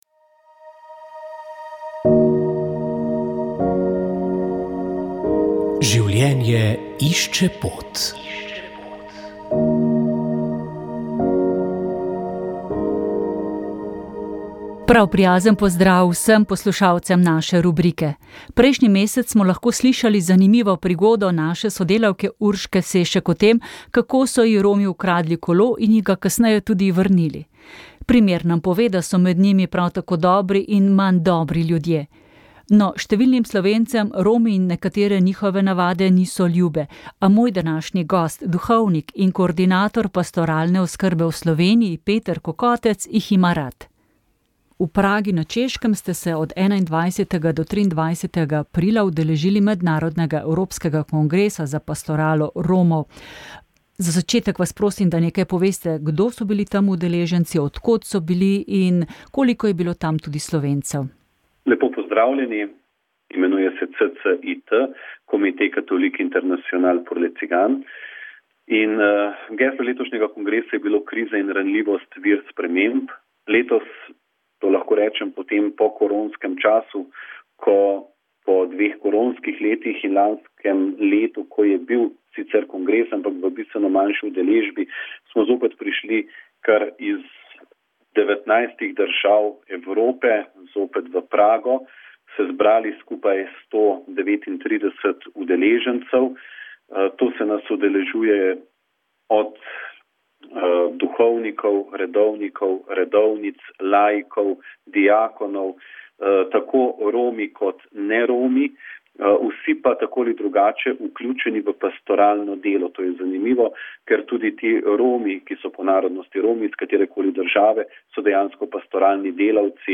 Slovenska akademija znanosti in umetnosti je ob stoti obletnici rojstva akademika Alojza Rebule pripravila znanstveni posvet z naslovom Alojz Rebula: Slovenec med zgodovino in nadčasnim.